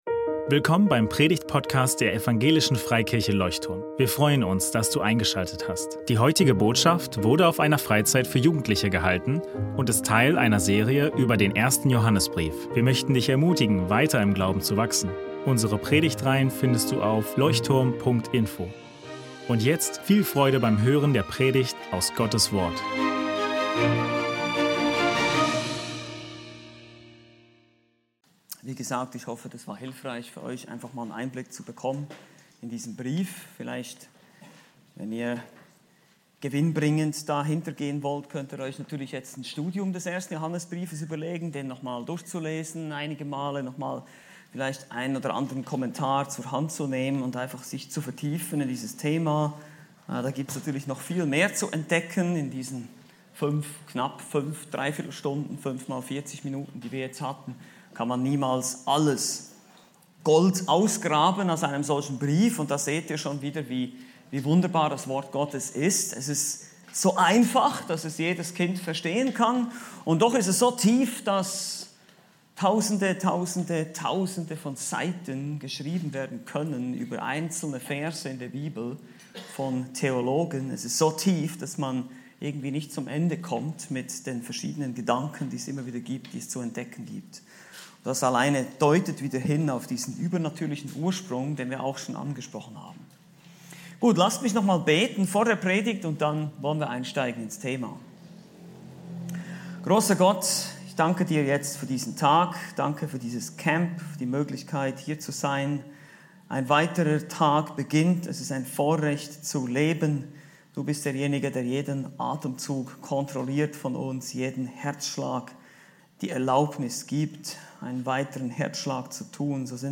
Unentschieden ist keine volle Gewissheit ~ Leuchtturm Predigtpodcast Podcast